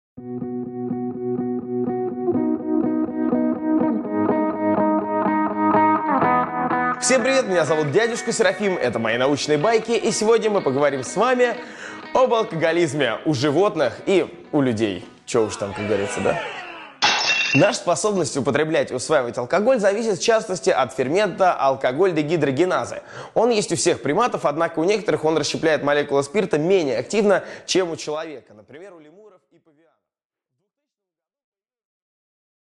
Аудиокнига Алкоголизм у людей и животных | Библиотека аудиокниг